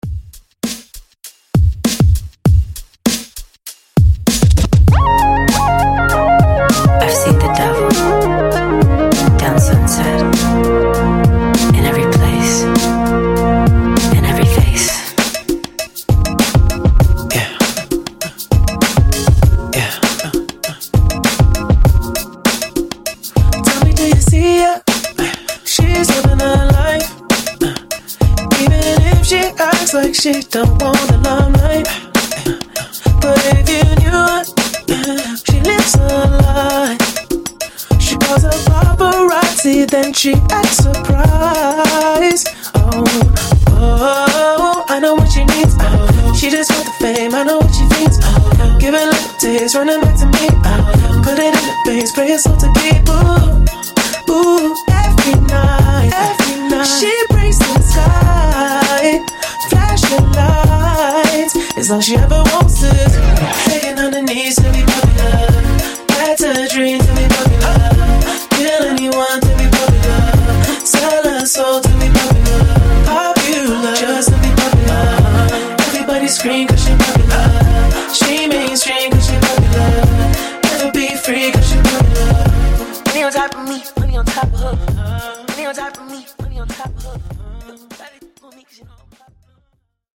Genre: R & B
Dirty BPM: 99 Time